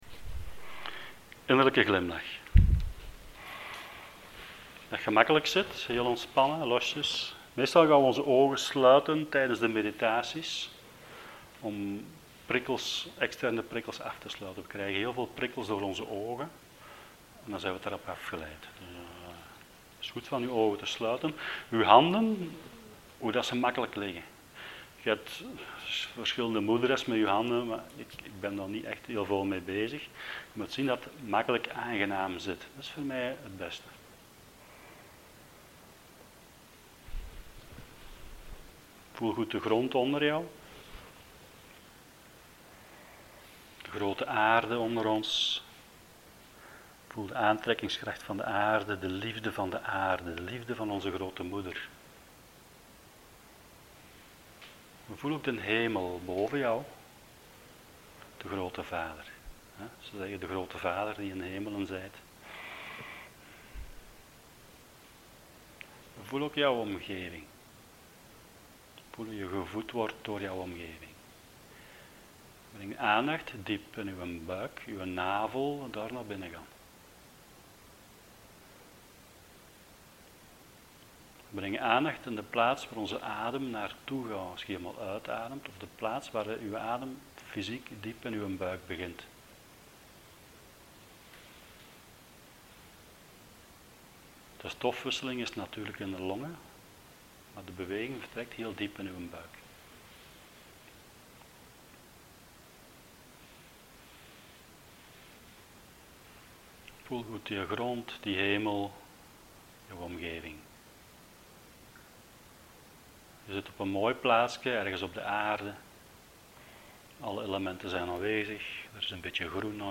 Hier staan een aantal opnames van meditaties.